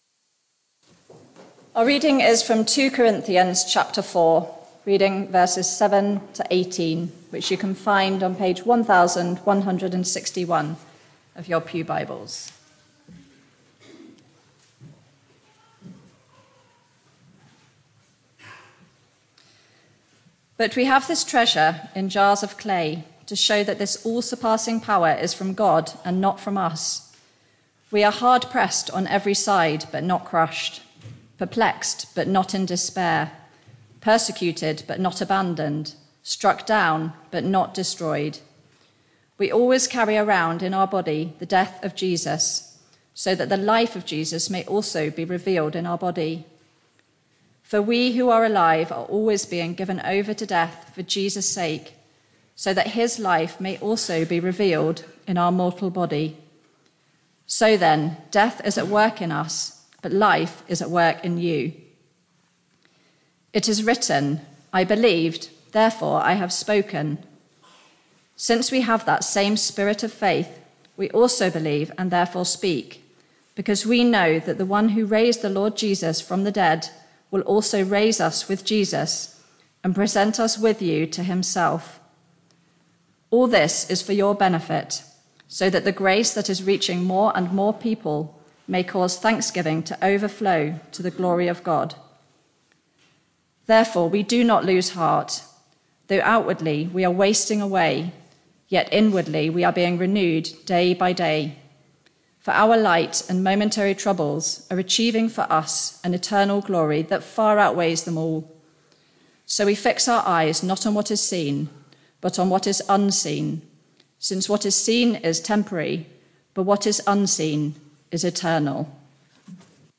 Media for Barkham Morning Service on Sun 22nd Oct 2023 10:00
Reading & Sermon only